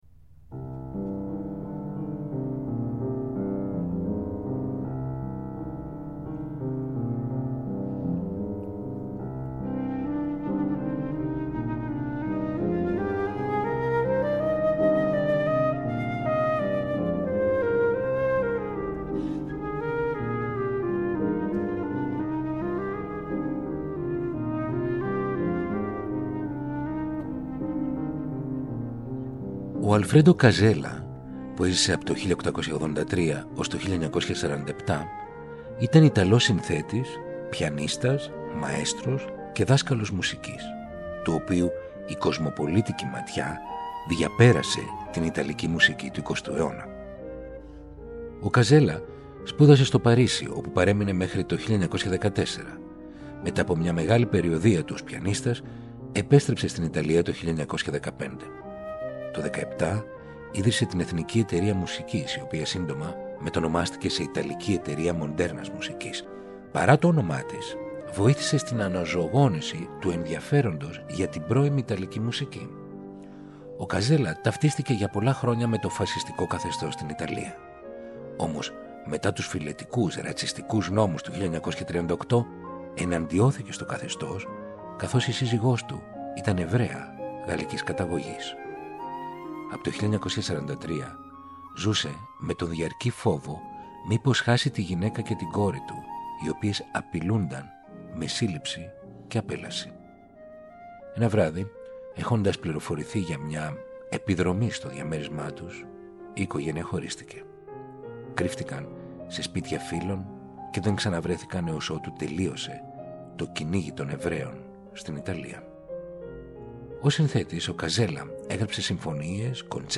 Η 5η, στη μεταγραφή του Αλφρέντο Καζέλα για πιάνο, για τέσσερα χέρια, οι Επτά Μεταμορφώσεις της 6ης του Γιαν Νοβάκ και το θέμα από το Αλεγκρέτο της 7η σε διασκευή για κιθάρα του Φρανθίσκο Τάρεγα.